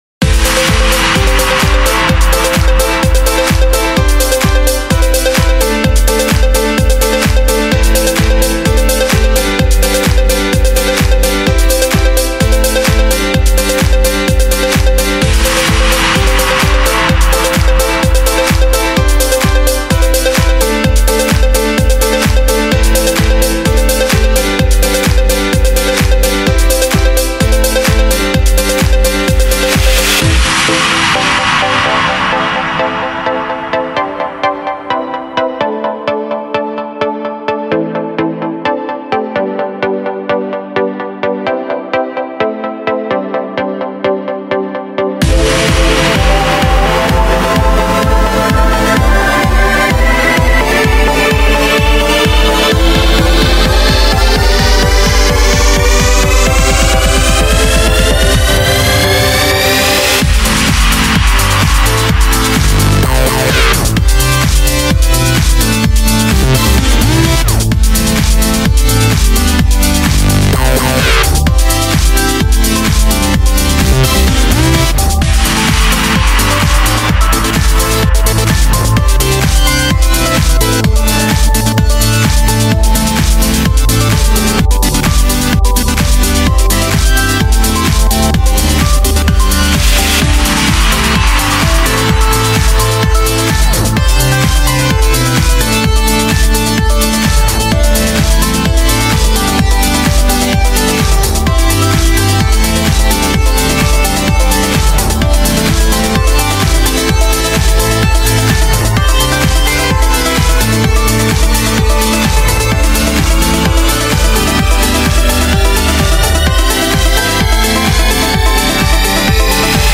Oh and this is the first time I tried to do electro house.